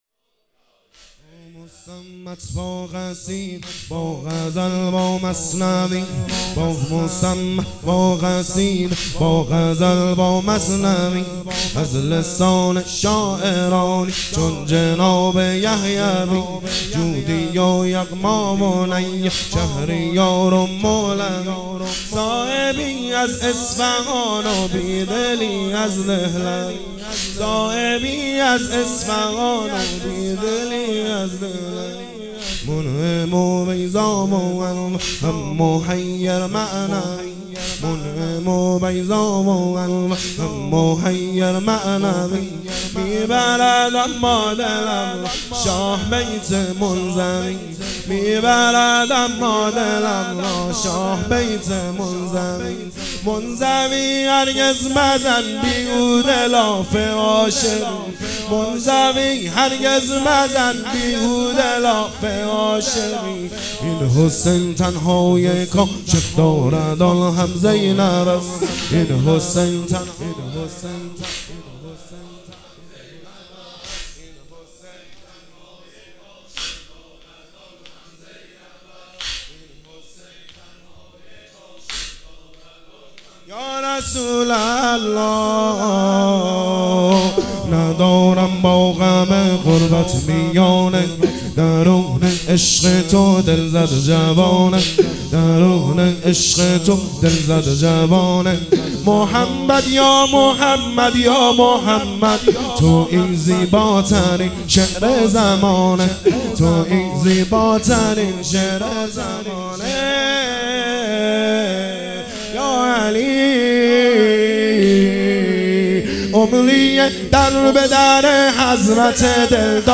واحد _ عمریه در به در حضرت دلدارم
شهادت حضرت رباب (س)1403